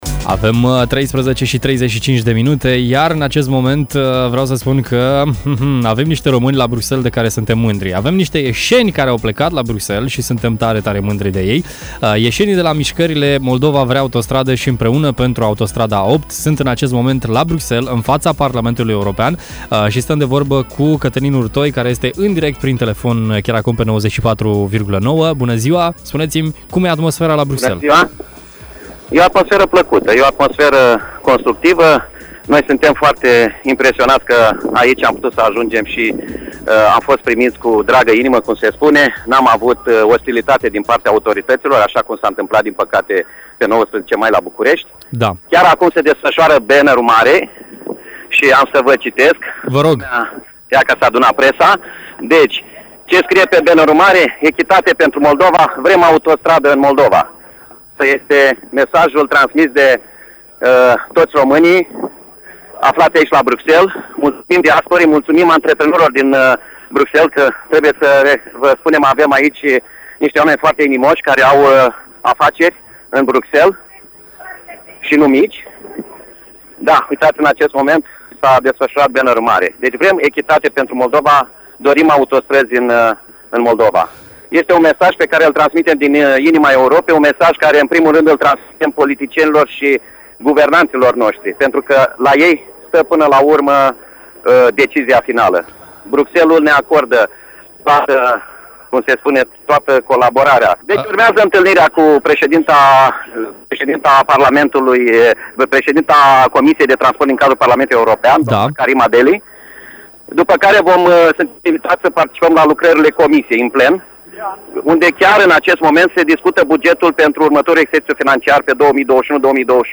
Mai multe detalii am aflat în direct la Radio Hit